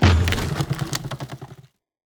car-wood-impact-02.ogg